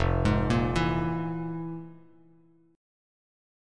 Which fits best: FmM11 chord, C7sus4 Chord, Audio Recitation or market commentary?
FmM11 chord